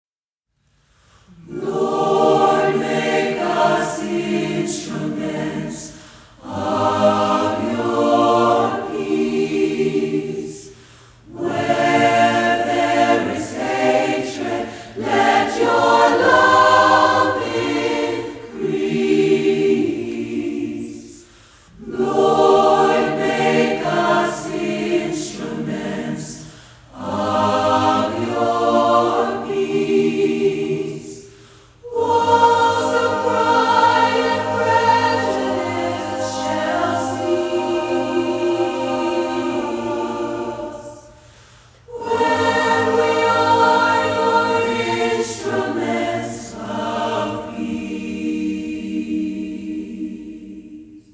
장르: Funk / Soul, Pop
스타일: Gospel, Vocal